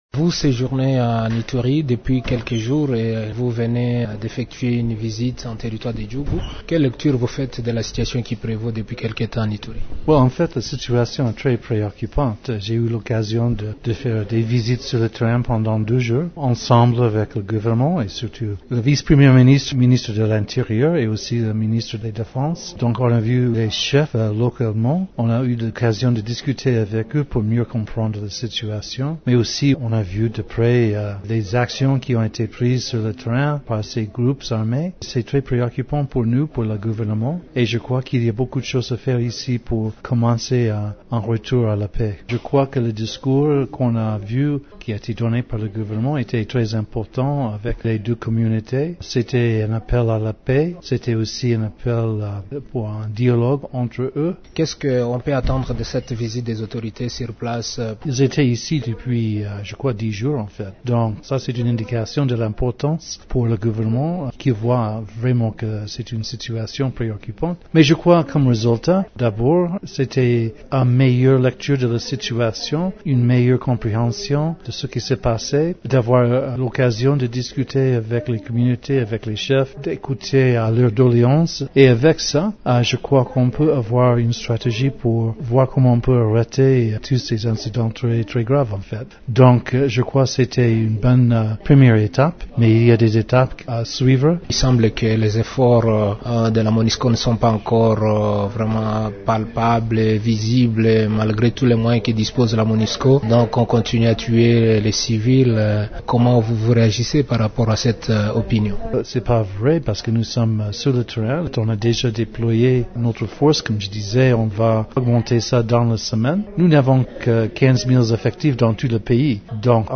David Gressly s’entretient